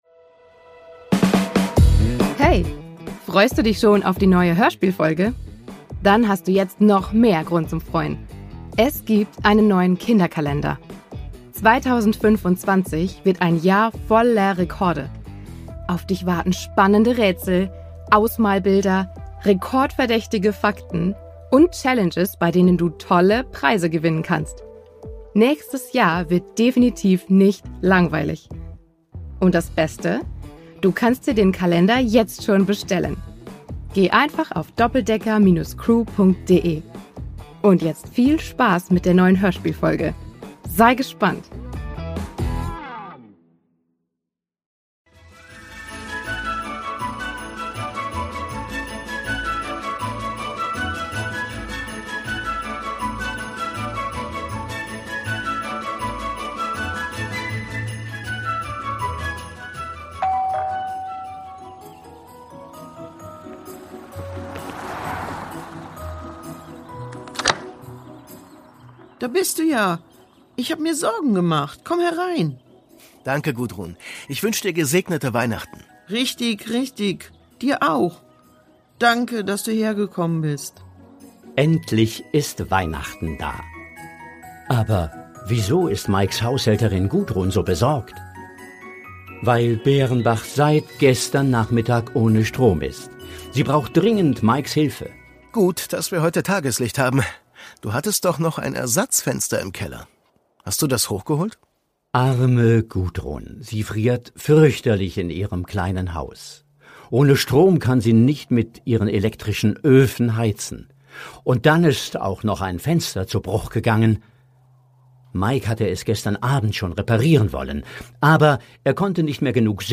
Weihnachten 4: Weihnachten im Dunkeln? | Die Doppeldecker Crew | Hörspiel für Kinder (Hörbuch) ~ Die Doppeldecker Crew | Hörspiel für Kinder (Hörbuch) Podcast